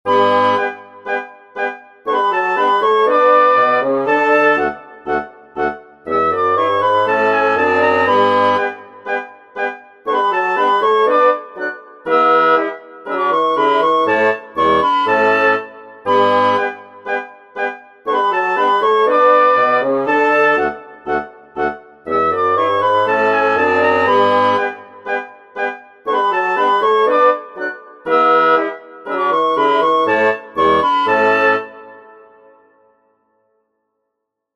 16edo wind quintet in G anti-dorian
16edo_demo.mp3